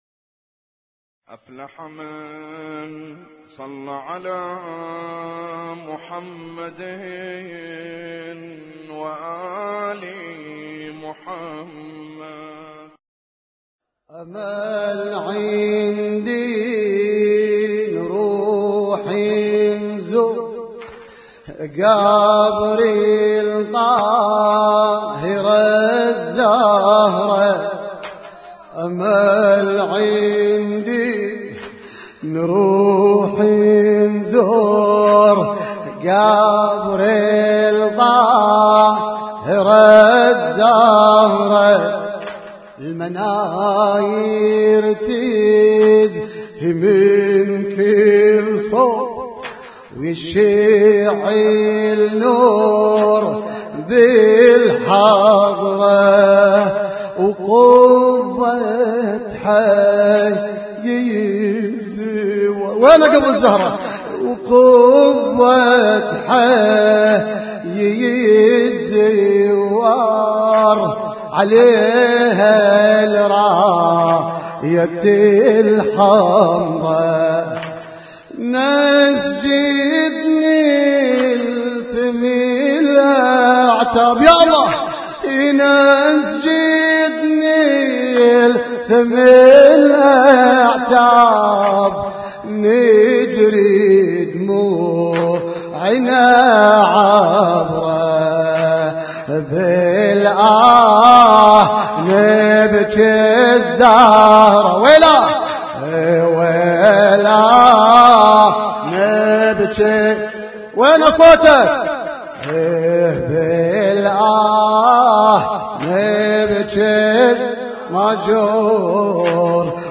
Husainyt Alnoor Rumaithiya Kuwait
لطم ليلة شهادة فاطمة الزهراء عليها السلام 1436